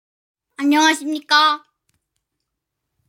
発音のポイントですが、アンニョンハセヨの「ニョン」の部分を意識し、息を口の外ではなく鼻に通すように「鼻音化」（ㅇ）を意識してください。